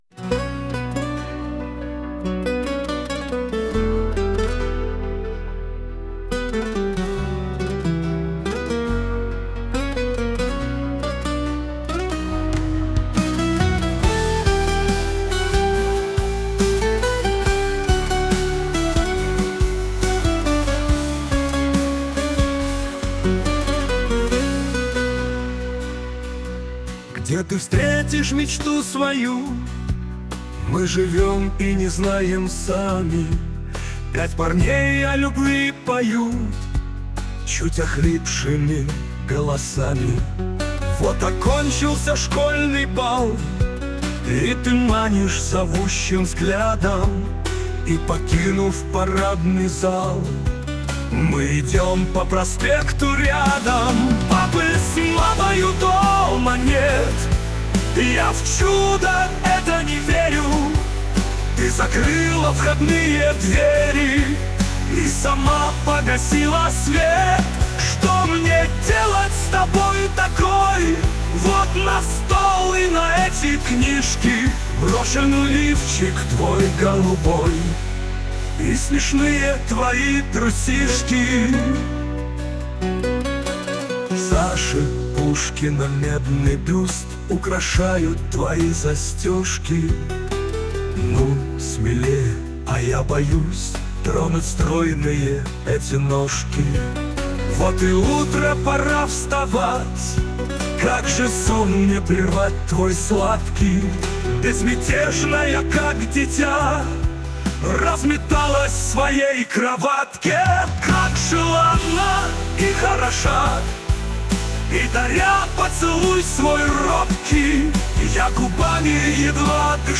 По мне...из двора перешли в эстраду.
pyat-parney-o-lyubvi-poyut.mp3